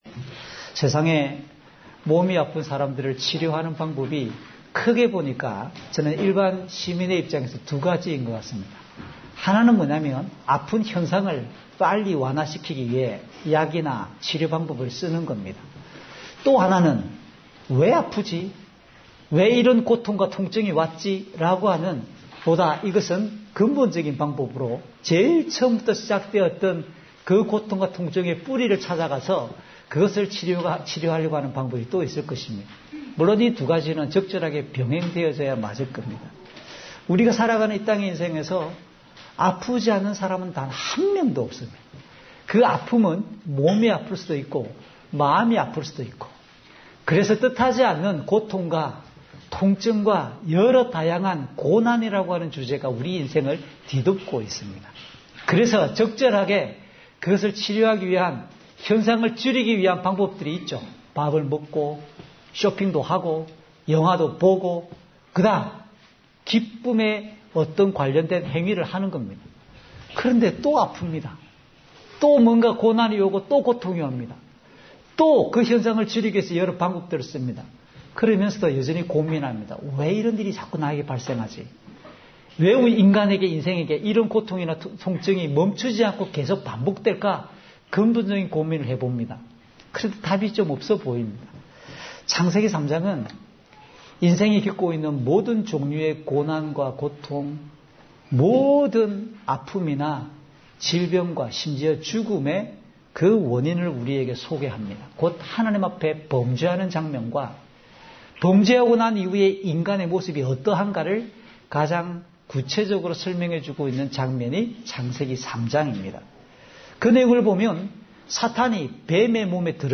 주일설교 - 2020년 10월 25일 "그리스도의 복음으로 참된 평강을 누립니다!"(요20:19~31)